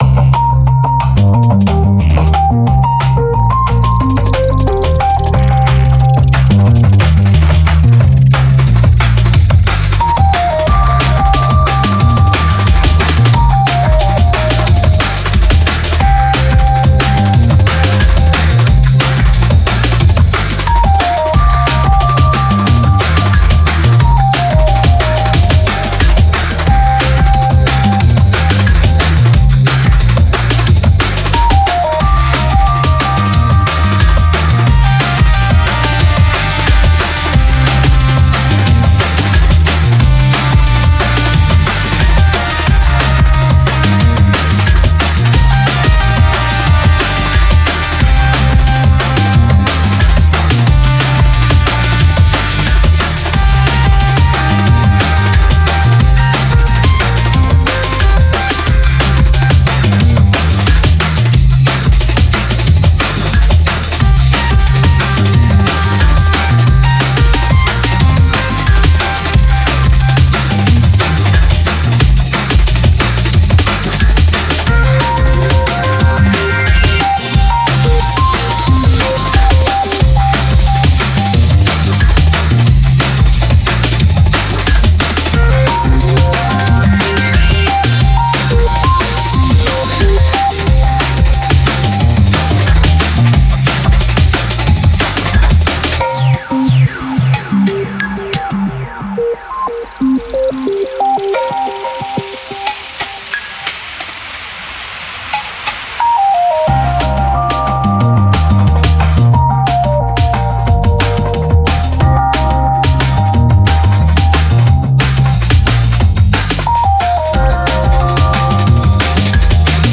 Genre Experimental